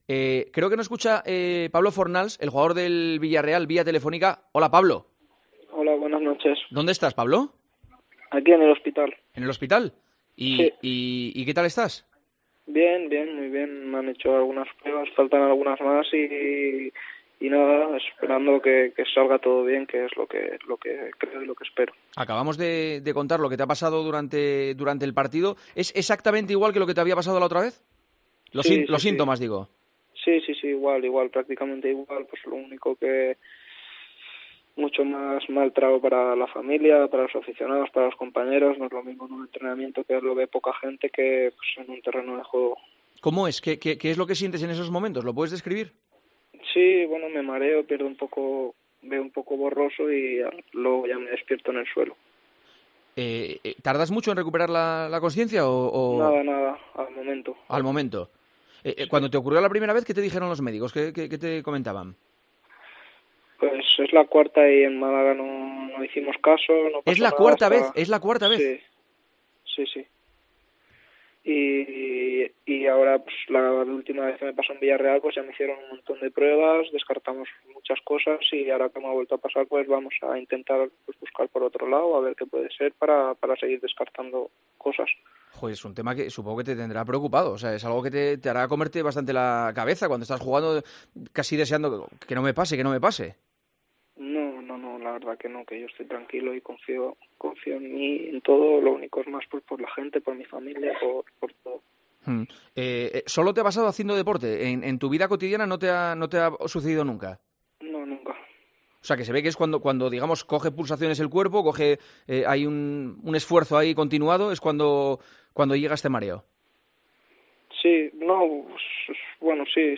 Entrevista en El Partidazo de COPE
Pablo Fornals atendió la llamada de El Partidazo de COPE desde el hospital donde se le practicaban pruebas tras caer desplomado sobre el césped durante el Villarreal - Athletic: "Estoy tranquilo, confío en mí. Lo pasa peor la familia, pero lo dejamos todo en manos de los doctores".